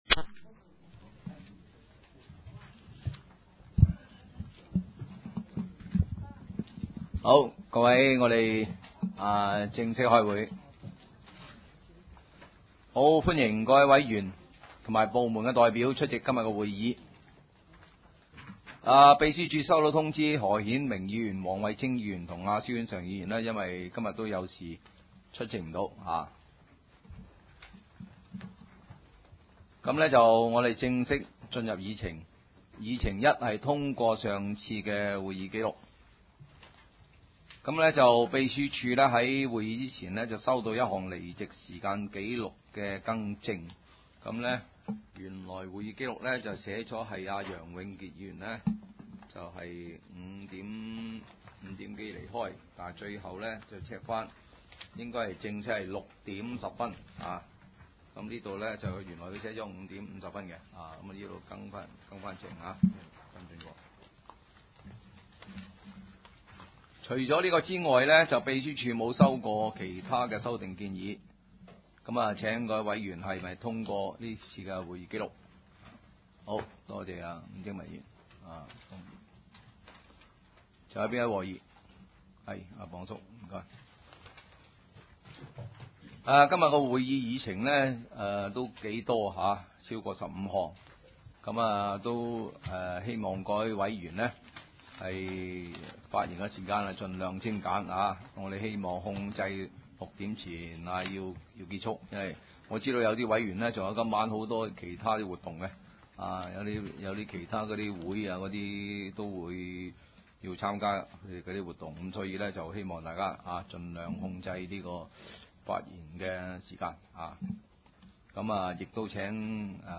九龍城區議會轄下 食物環境生事務委員會 (食環會) 第五次會議 日期 : 2008年10月9日 (星期四) 時間 : 下午2時30分 地點 : 九龍紅磡德豐街18-22號 海濱廣場一座 17樓 九龍城民政事務處會議室 議 程 （會議錄音） 文件編號 1.